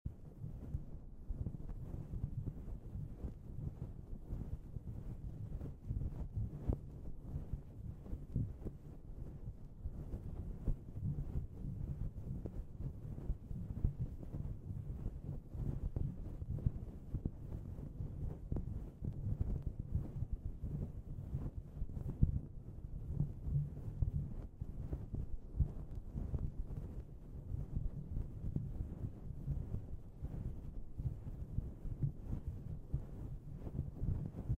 Mic scratches to help you sound effects free download
Mic scratches to help you fall asleep.